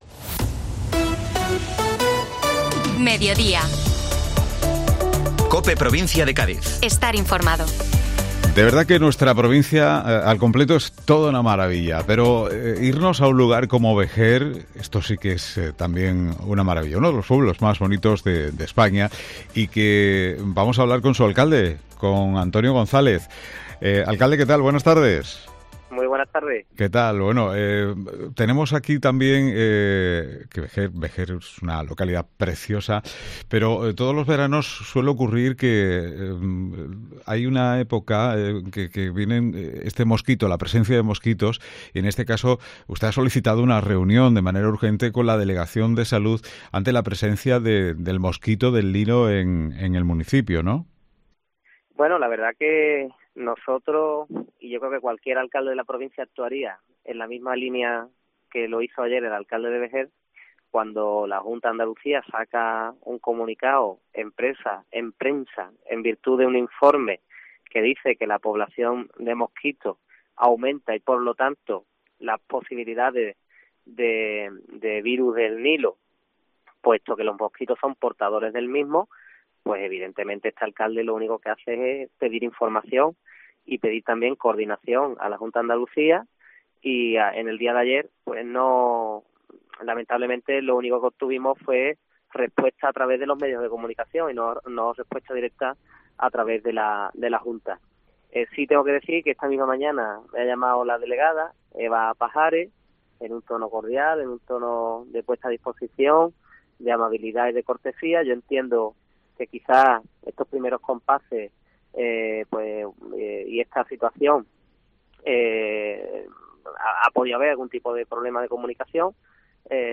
Antonio González, Alcalde de Vejer - Actuaciones contra los mosquitos y ocupación meses de verano